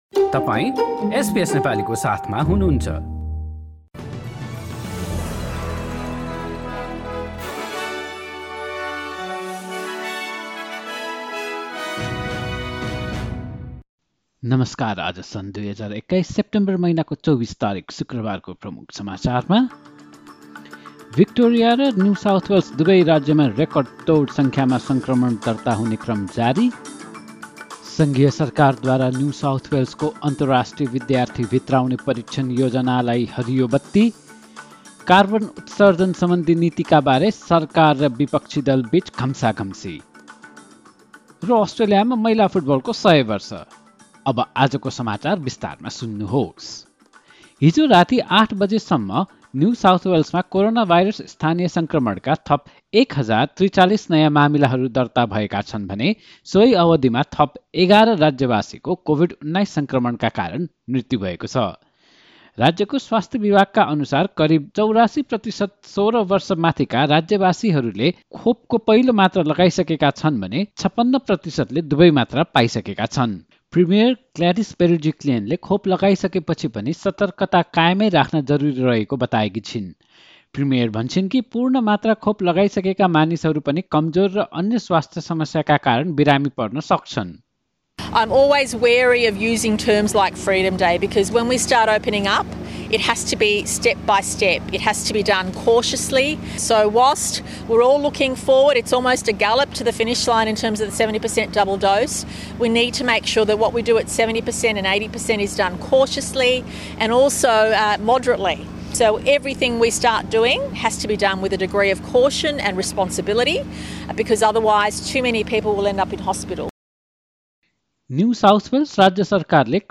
एसबीएस नेपाली अस्ट्रेलिया समाचार: शुक्रवार २४ सेप्टेम्बर २०२१